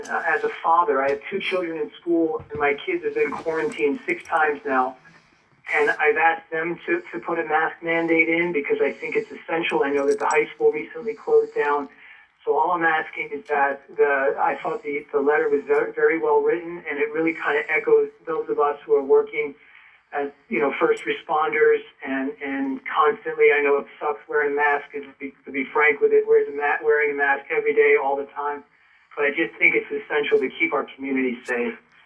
Mobridge City Council discusses mask policies